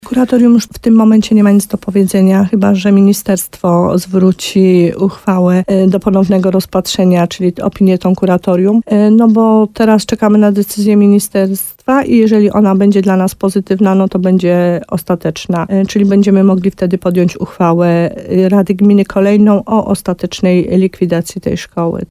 Wójt gminy Podegrodzie Małgorzata Gromala, przekonywała w programie Słowo za Słowo na antenie RDN Nowy Sącz, że będzie to możliwe, jeśli procedura likwidacji zostanie przeprowadzona do końca.